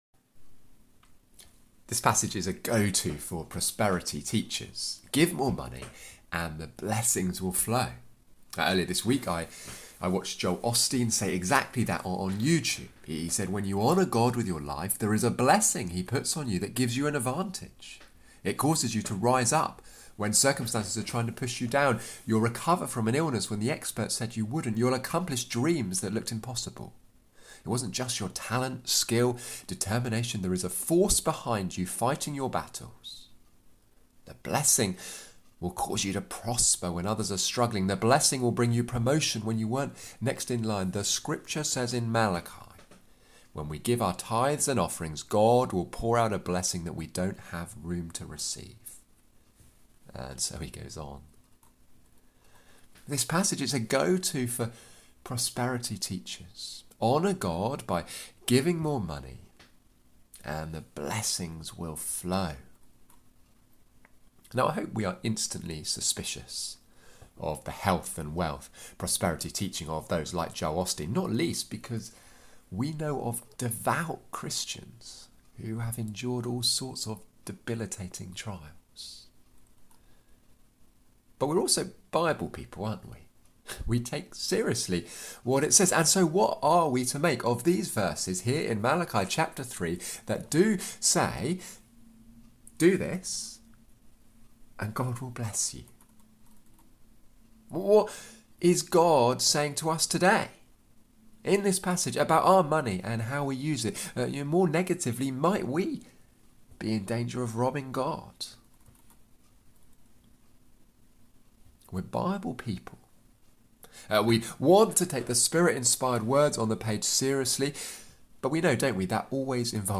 Not taking God at his word Passage: Malachi 3:6-12, Galatians 3:10-14 Service Type: Weekly Service at 4pm « Where is the God of Justice?